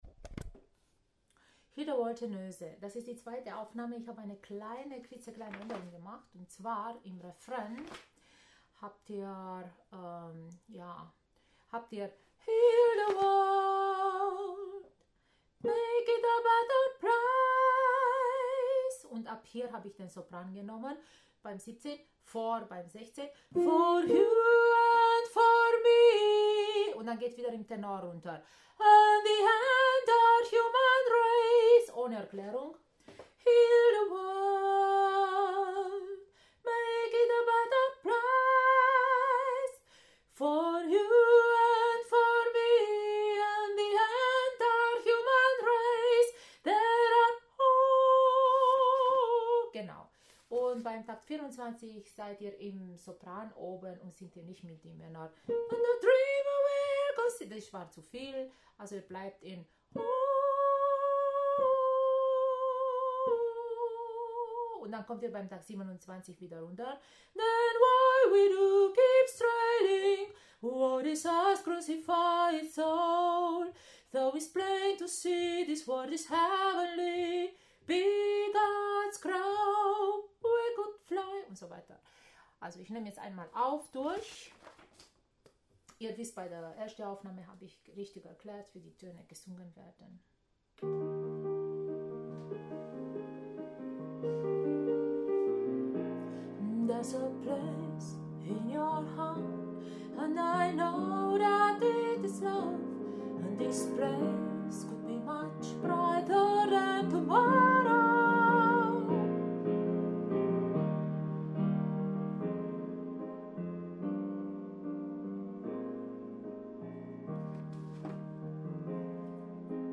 06 - Tenösen - ChorArt zwanzigelf - Page 2
06 - Tenösen - ChorArt zwanzigelf - Page 2 | Der moderne Chor in Urbach